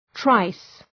Προφορά
{traıs}